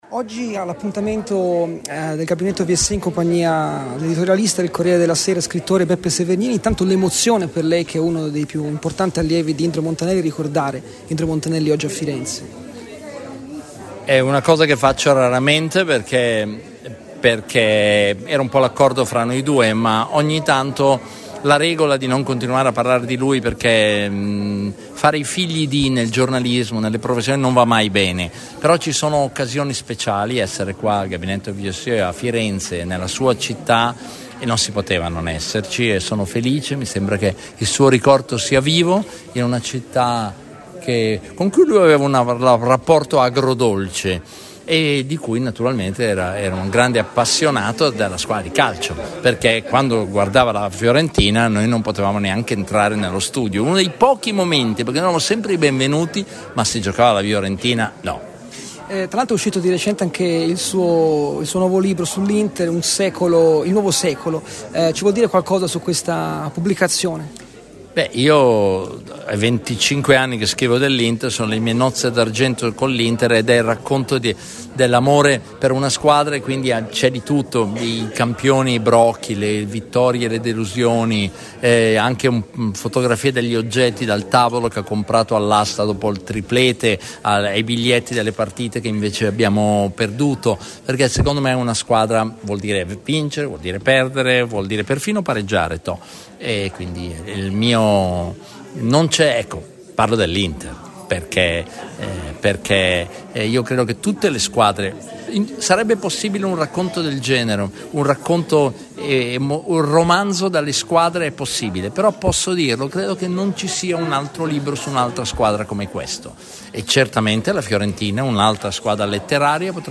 Beppe Servergnini a Radio FirenzeViola